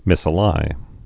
(mĭsə-lī)